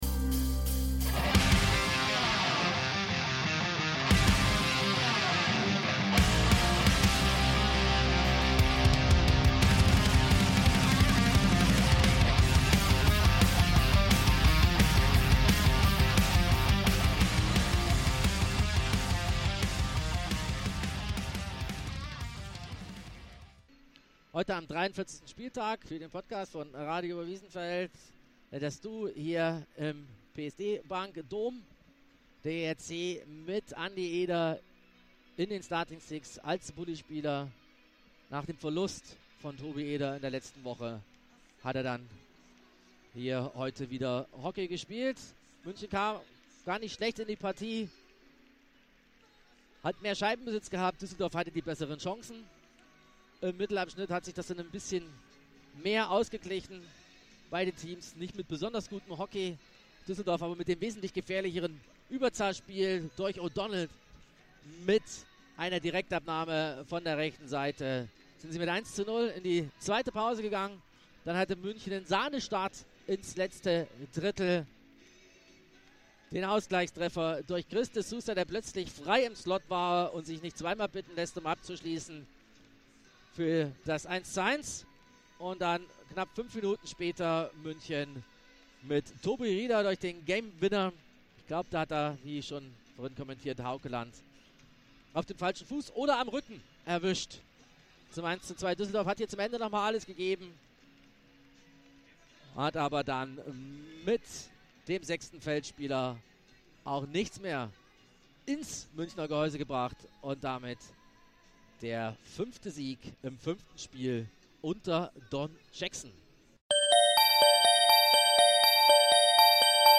Zudem hört Ihr die letzten Live-Minuten aus dem Spiel gegen Köln.
Den Link zu PayPal von Radio Oberwiesenfeld findet Ihr da: PayPal Radio Oberwiesenfeld Wir danken dem EHC Red Bull München für die Möglichkeit, bei der PK dabei zu sein, diese und anschließende Interviews aufzuzeichnen und diese Aufnahmen im Rahmen unseres Podcasts verwenden zu dürfen.